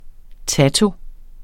Udtale [ ˈtato ]